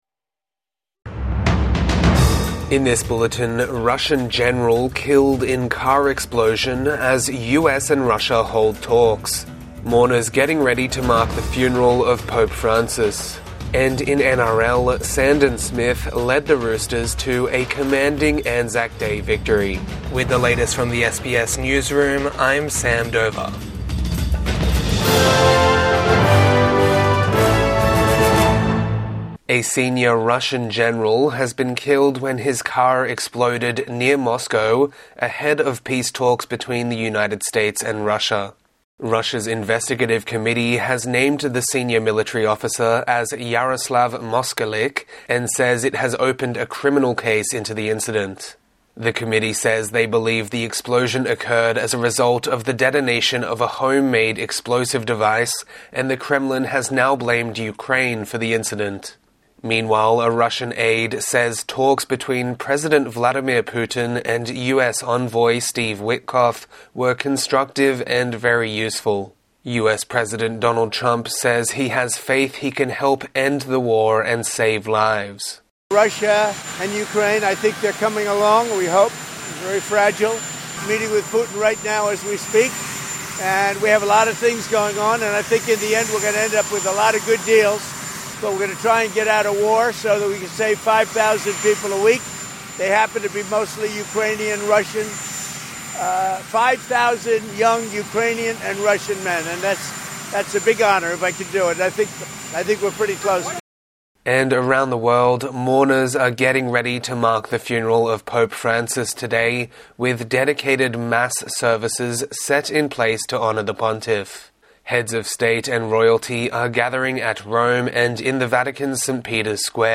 Russian general killed in explosion as US envoys meets Putin | Morning News Bulletin 26 April 2025